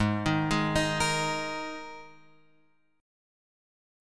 G# Suspended 2nd
G#sus2 chord {4 6 6 x 4 6} chord